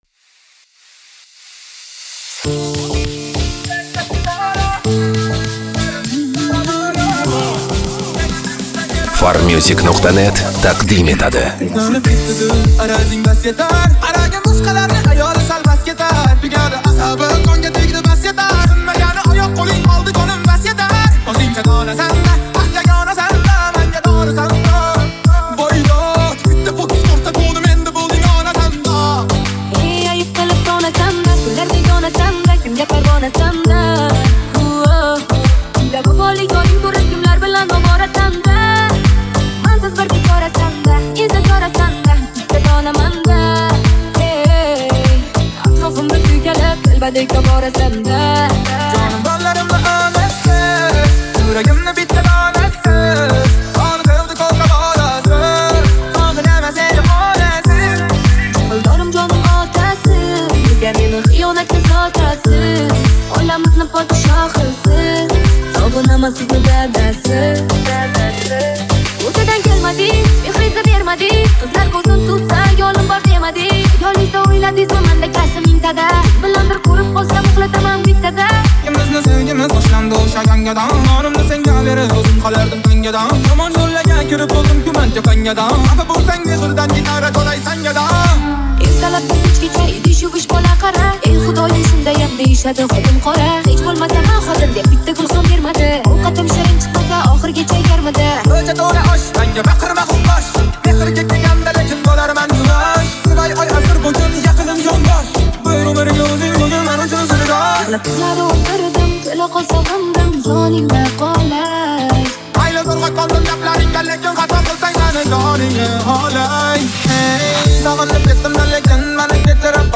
Узбекский песни